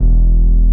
16BASS01  -R.wav